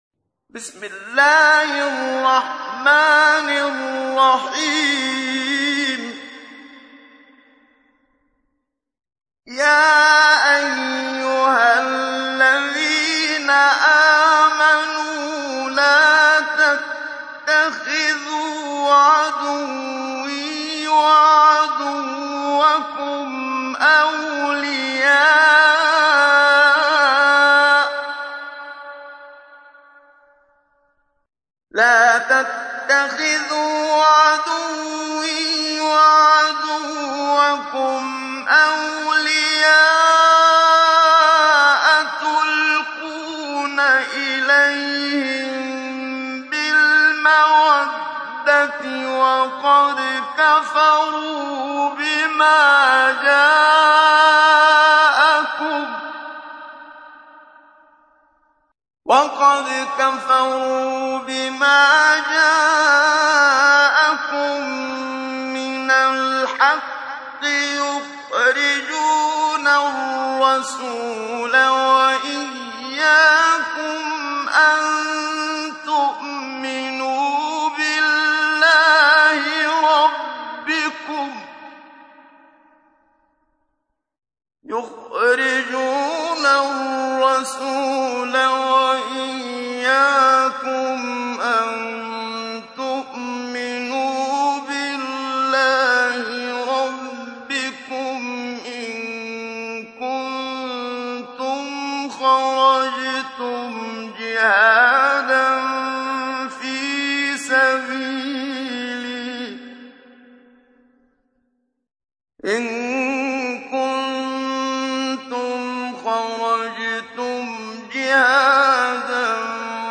تحميل : 60. سورة الممتحنة / القارئ محمد صديق المنشاوي / القرآن الكريم / موقع يا حسين